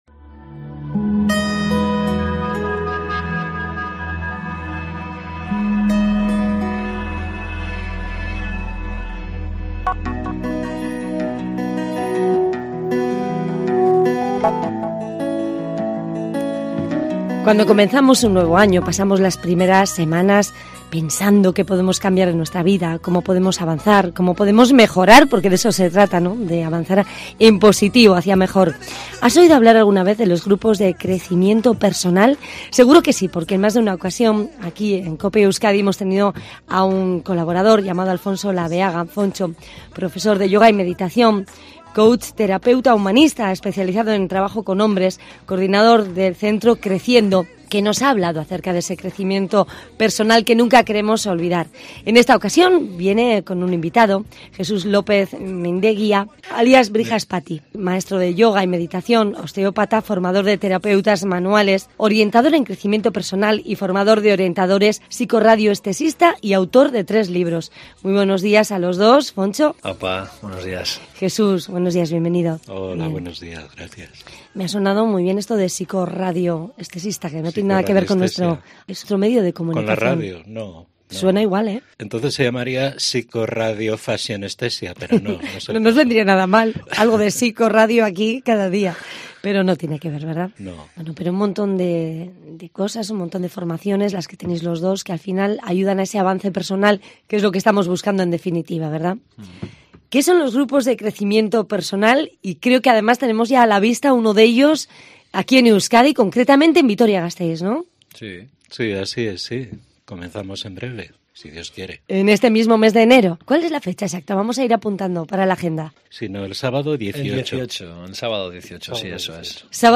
Terapeutas especializados explican cómo mejorar y conocerse
Hoy en COPE Euskadi hemos hablado con dos personas expertas en esa materia.